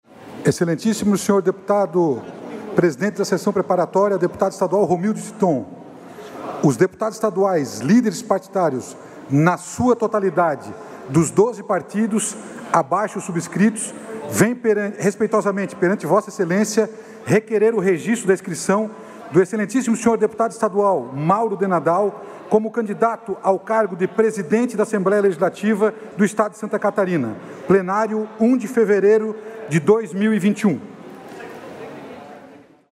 Confira os pronunciamentos dos deputados durante a sessão preparatória desta segunda-feira (1º) para a eleição do presidente e da Mesa Diretora da Assembleia Legislativa para o biênio 2021-2023.